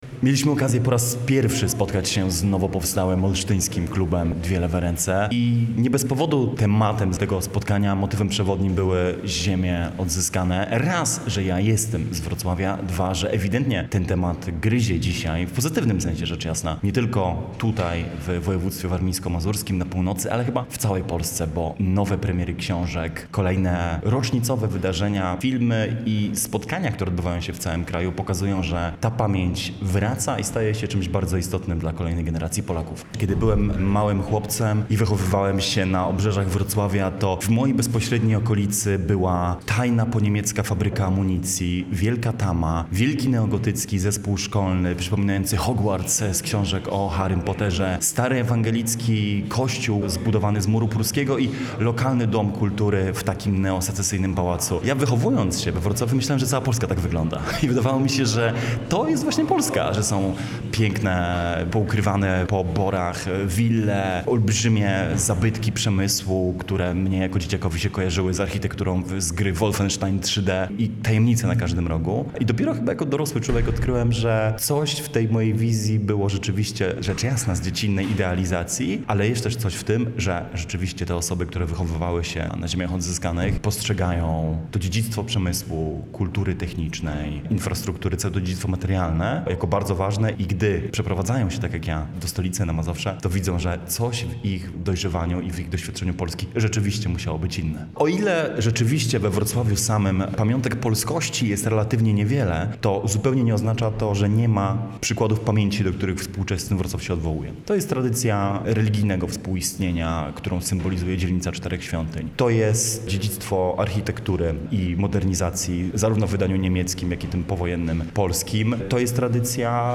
podczas spotkania z fanami w Olsztynie.